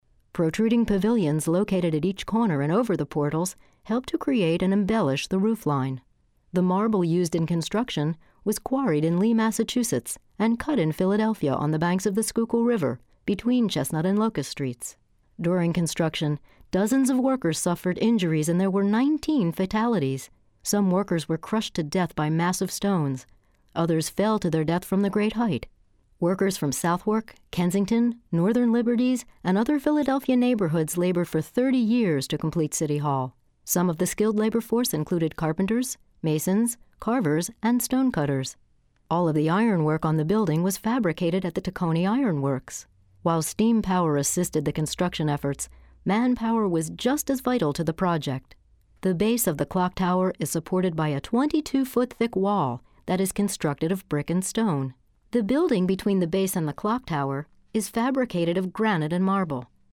City Hall VO (60 sec).mp3